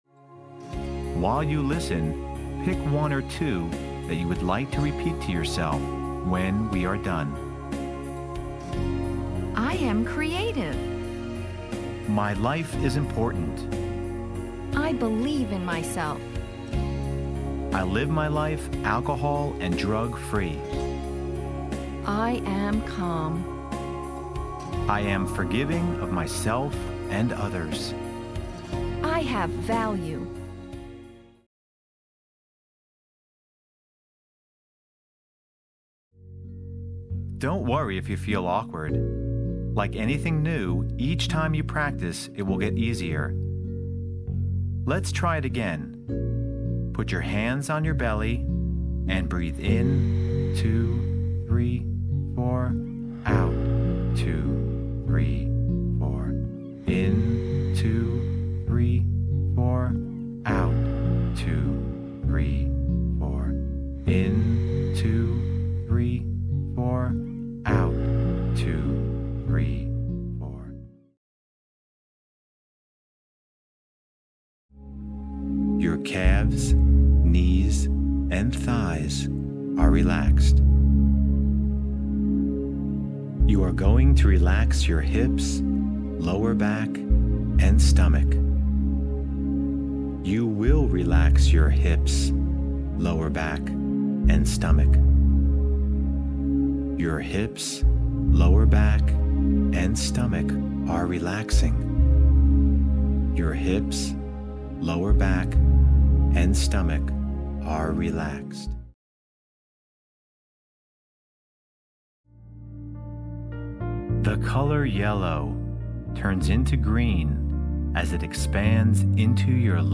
The techniques are accompanied by soothing, uplifting music to further enhance your teenager’s relaxation experience.
Male narration is accompanied by uplifting and relaxing music.